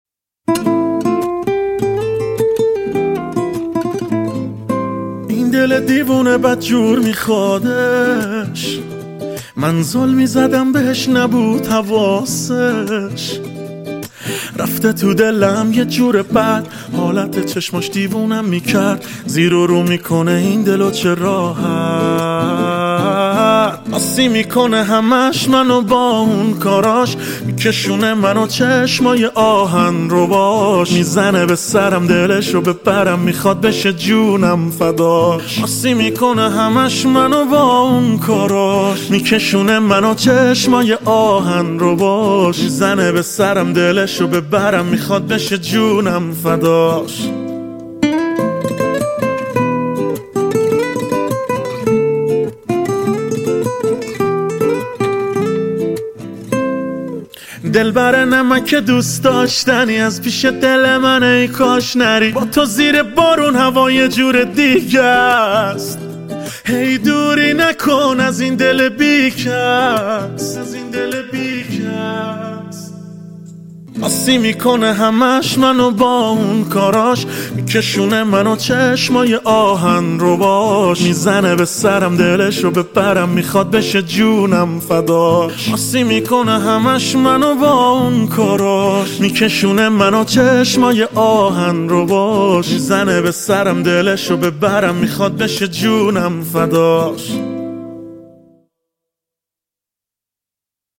گیتار
تک اهنگ ایرانی